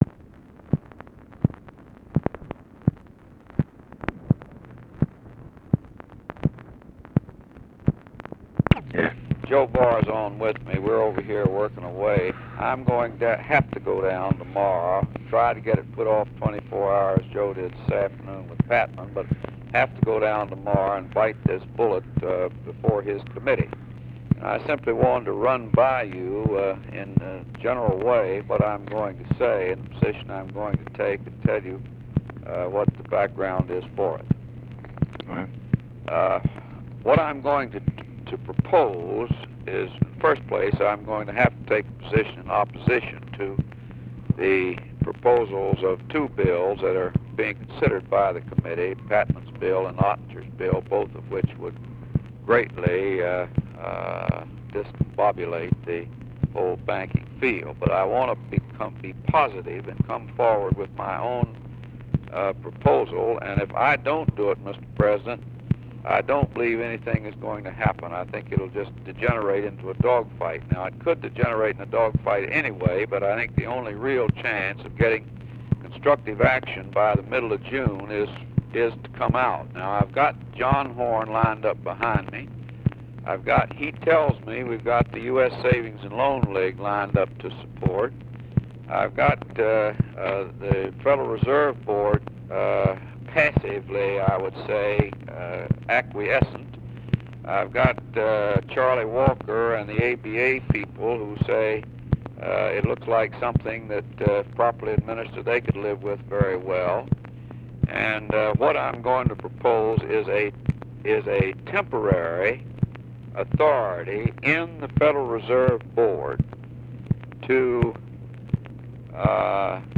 Conversation with HENRY FOWLER and JOSEPH W. BARR, May 19, 1966
Secret White House Tapes